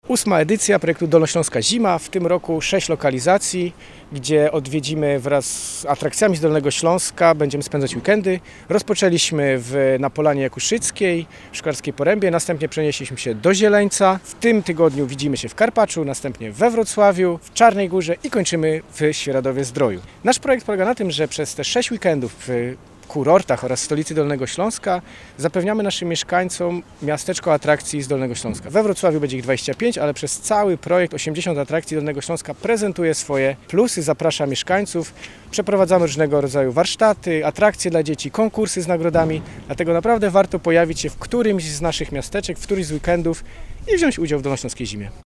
Wrocław tym samym dołącza do projektu „Dolnośląska Zima”. Ma on na celu promocję regionu, jego atrakcji turystycznych, co przekłada się na liczbę odwiedzających – podkreśla Michał Rado, wicemarszałek Województwa Dolnośląskiego.